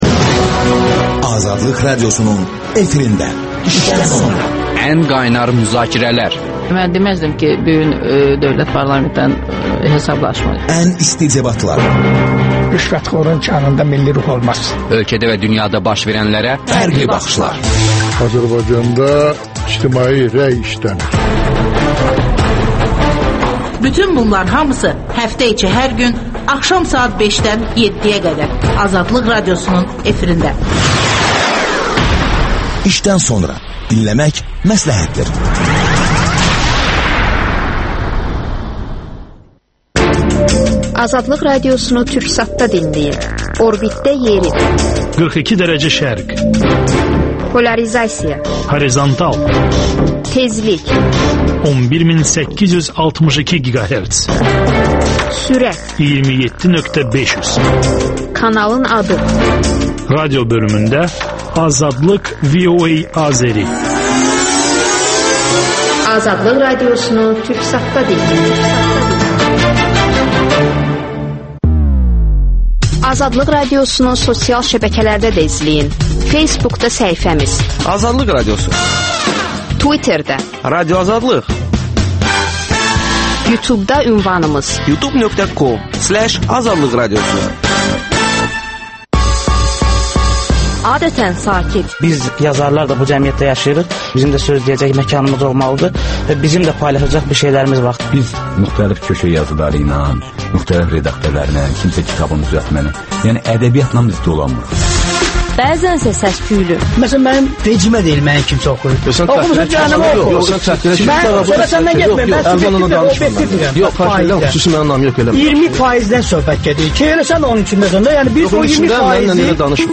Beynəlxalq Yayım Şurasının (BBG) üzvü, səfir Viktor Eş canlı efirdə suallara cavab verir